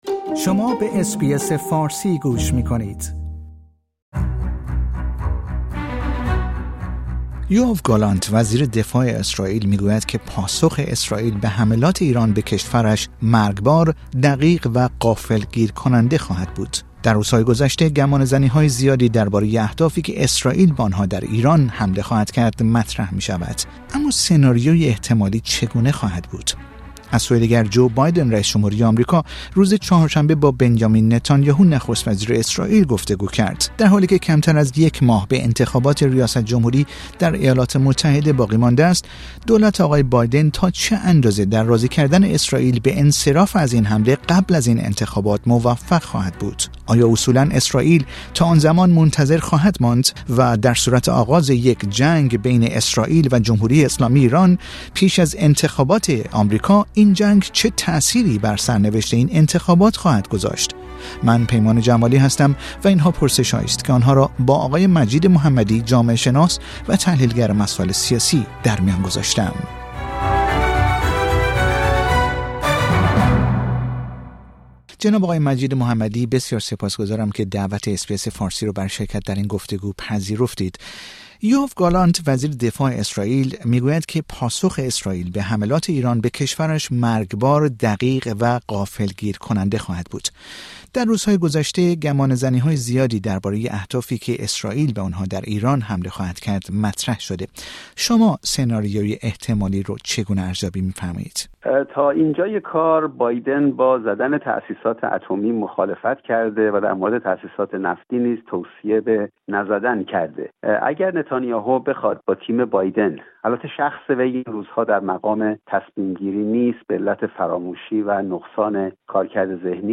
نظرات ارائه شده در این گفتگو نظرات کارشناس مربوطه است و نشانگر دیدگاه های اس بی اس فارسی نیست.